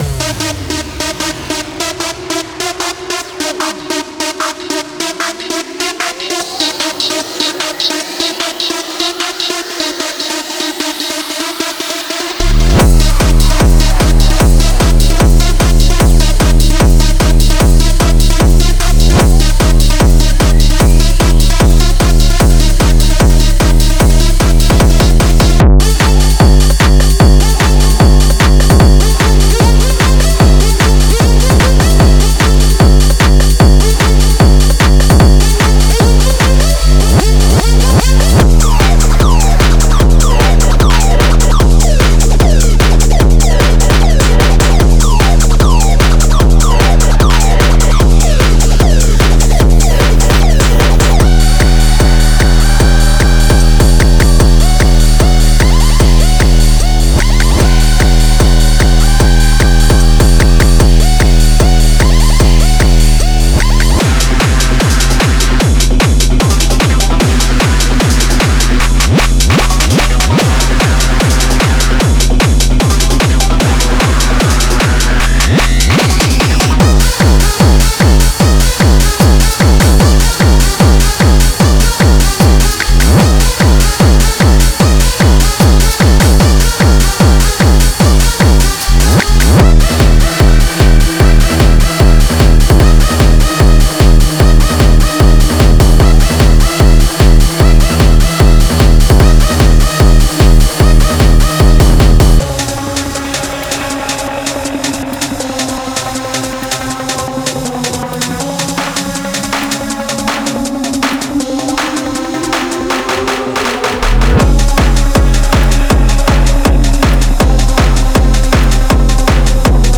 Genre:Hard Dance
150から160BPMのサンプルを収録した
デモサウンドはコチラ↓
49 Melodic Loops
25 Drum Loops
35 Bass Loops